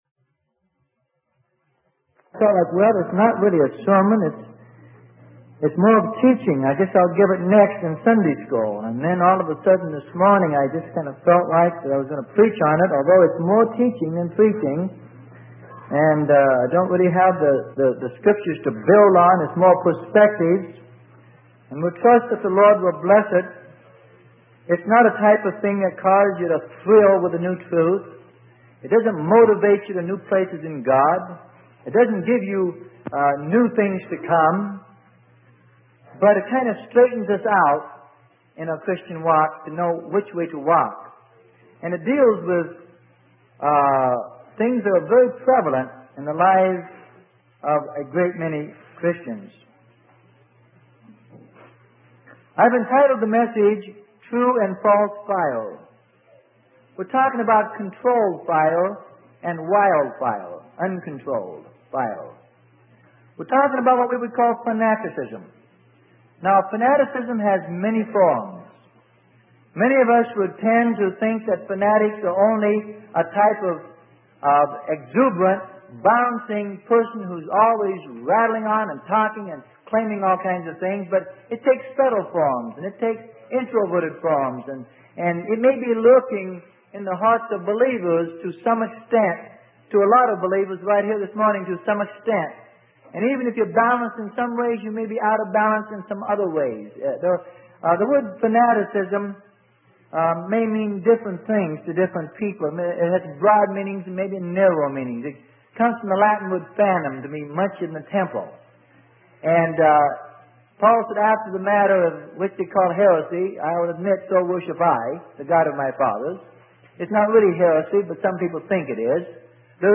Sermon: True and False Fire - Freely Given Online Library